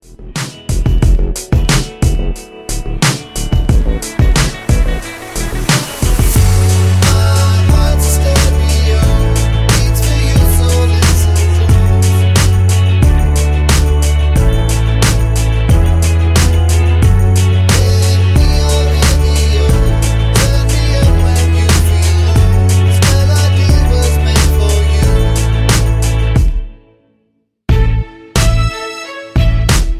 Backing track files: 2010s (1044)
Buy With Backing Vocals.